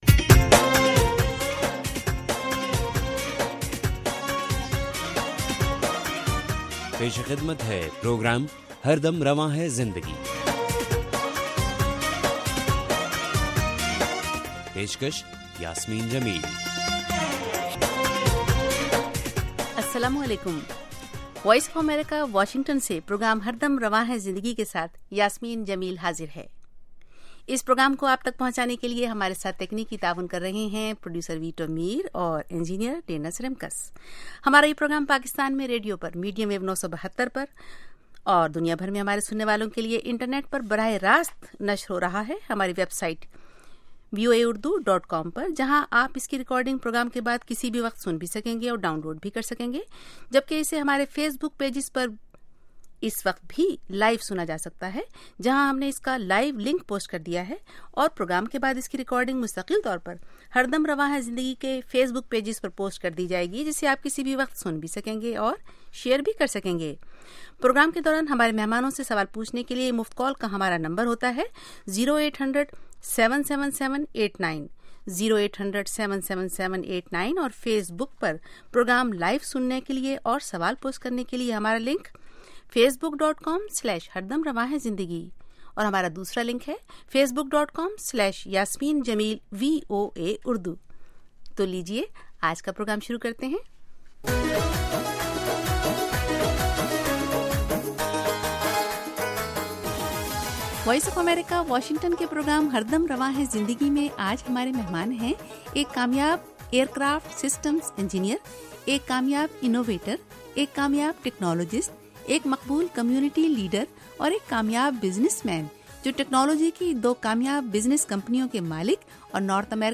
وائس آف امریکہ کے پروگرام ہر دم رواں ہے زندگی میں آج ہمارے مہمان ہیں